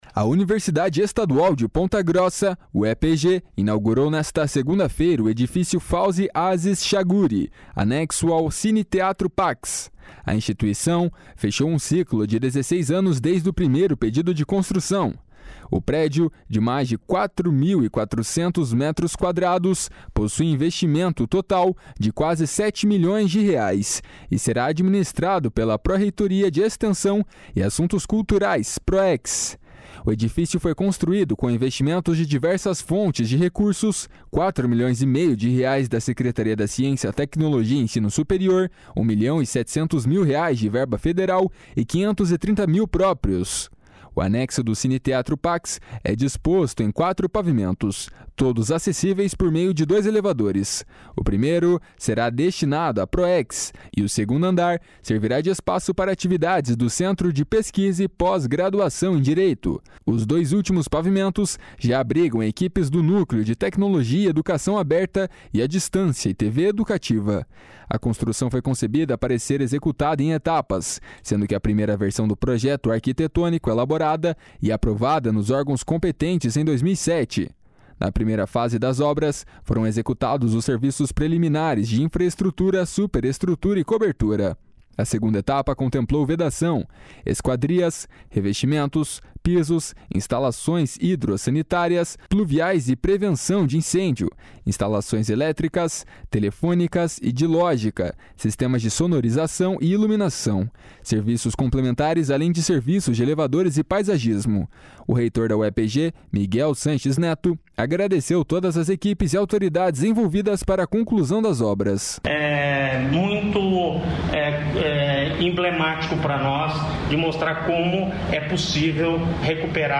O reitor da UEPG, Miguel Sanches Neto, agradeceu todas as equipes e autoridades envolvidas para a conclusão das obras. // SONORA MIGUEL SANCHES //